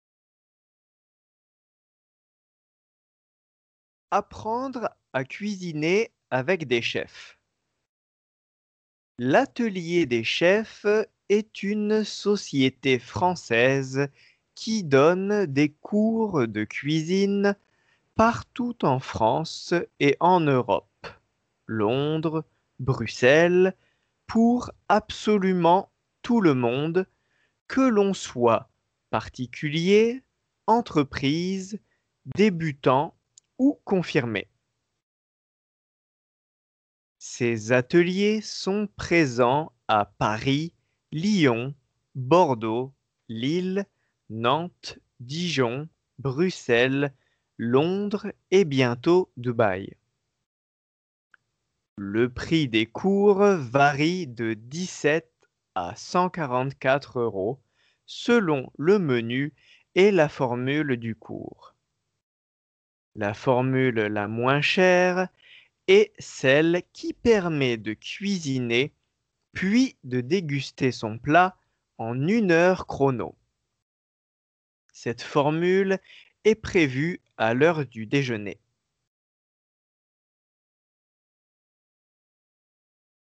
仏検２級　長文読解ー音声
練習用です。